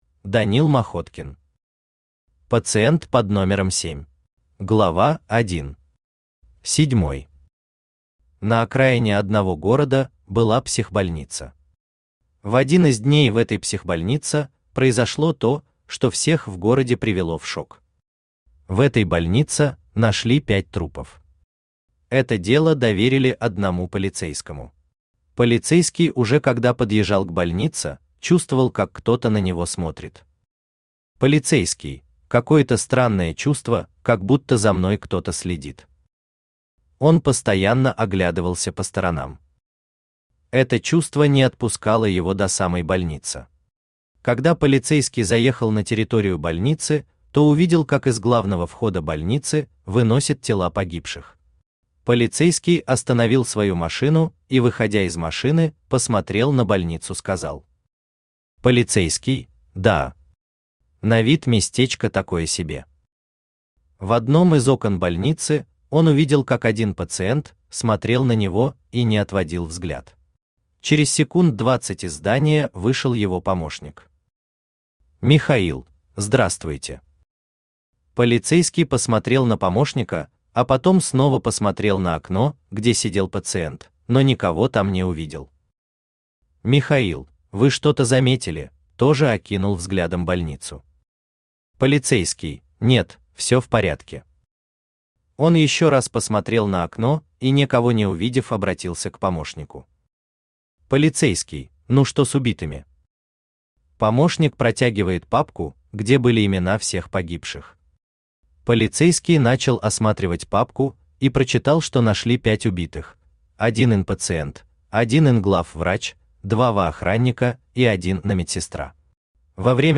Аудиокнига Пациент под номером семь | Библиотека аудиокниг
Aудиокнига Пациент под номером семь Автор Данил Махоткин Читает аудиокнигу Авточтец ЛитРес.